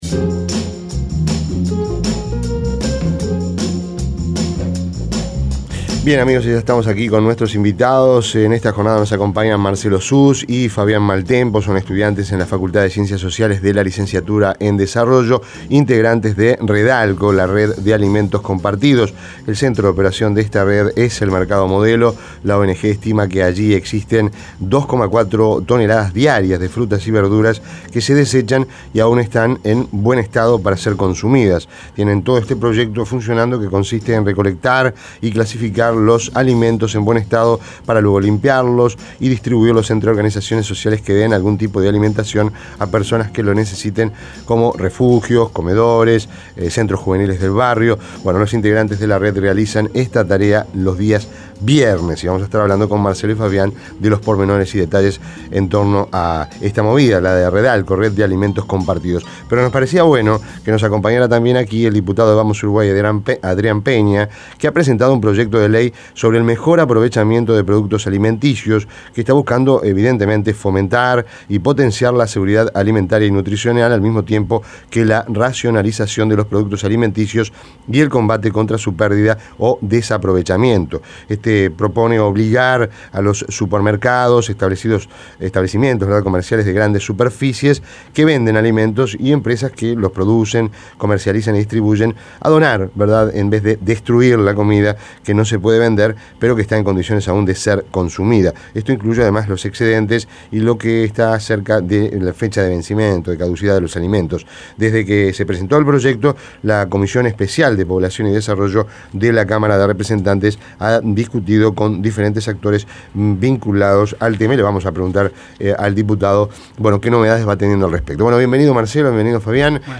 Entrevista en Rompkbzas Comida que no has de vender